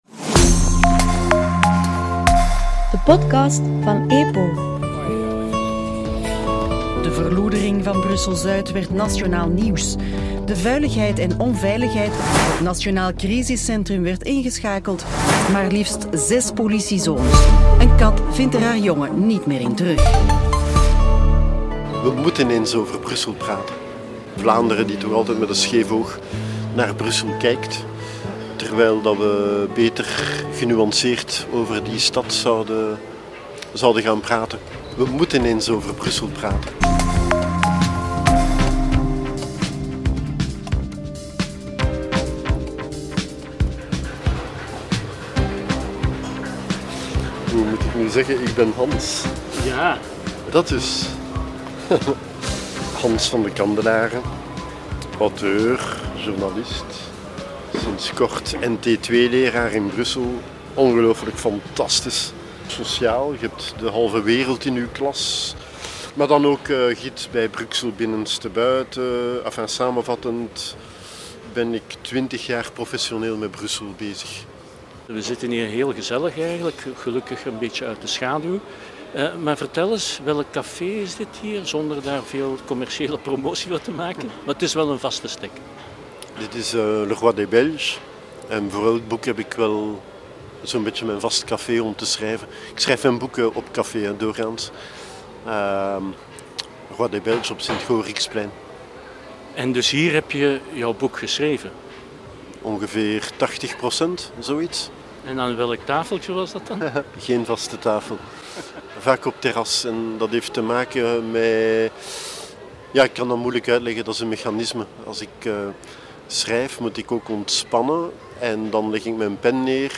Een gesprek over de Brusselse hyperdiversiteit, het belang van publieke ruimte, religie en de uitdagingen waarvoor Brussel staat.